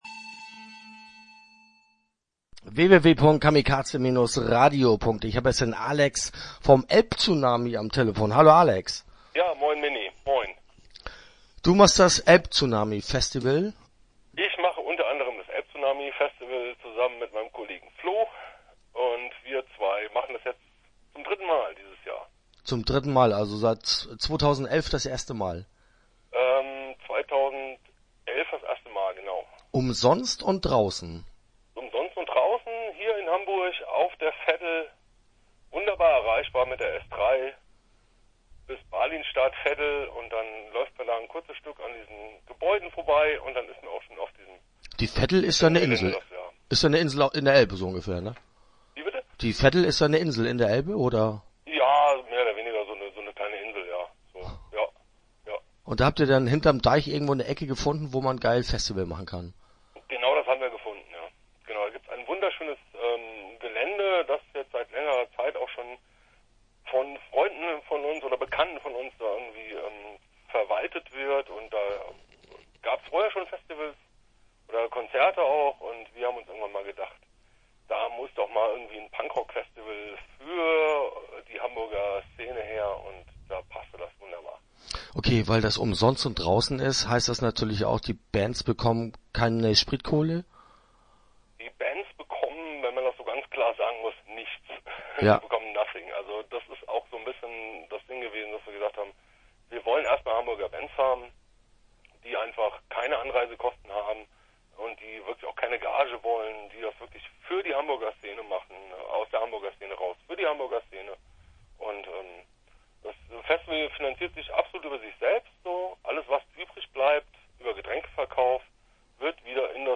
Start » Interviews » Elb-Tsunami Fesival